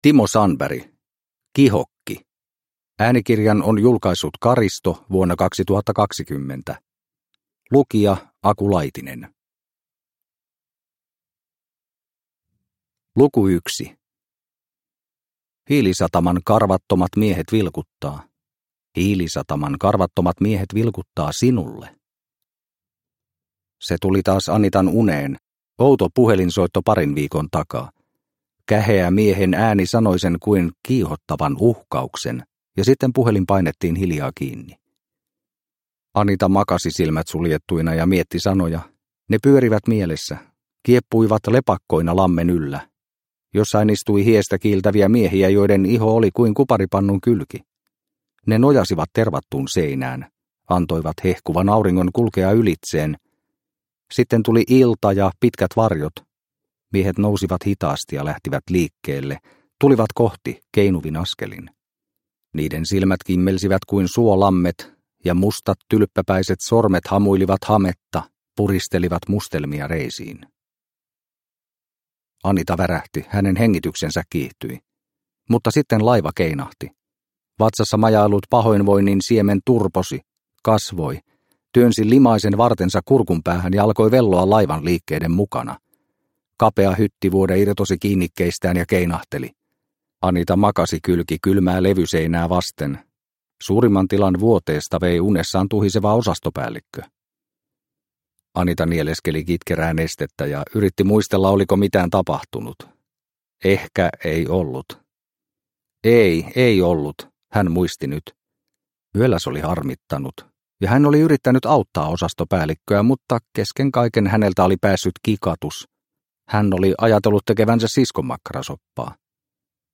Kihokki – Ljudbok – Laddas ner